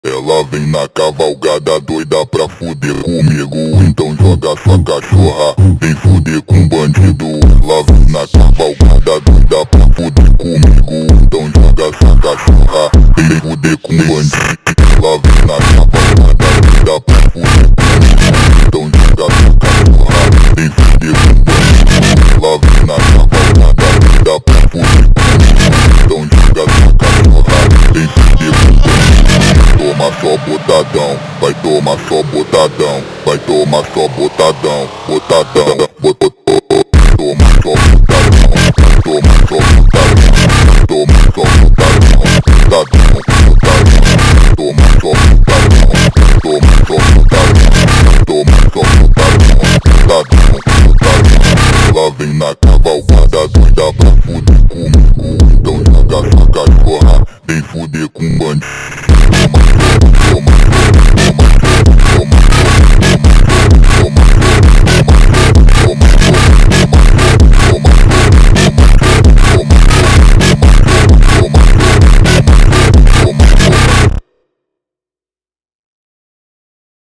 فانک🔥
ترکیبی از بیس سنگین، ریتم پرشتاب و فضای تیره